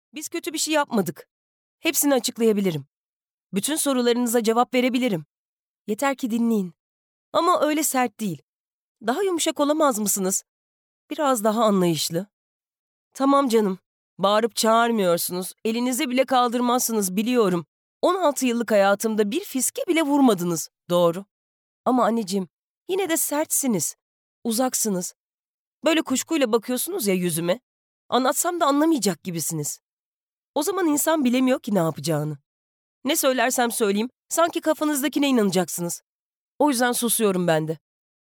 Sesli Kitap
Deneyimli seslendirme sanatçılarının okuduğu, editörlüğümüz tarafından özenle denetlenen sesli kitap koleksiyonumuzun ilk örneklerini paylaşmaktan sevinç duyuyoruz.